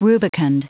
Prononciation du mot rubicund en anglais (fichier audio)
Prononciation du mot : rubicund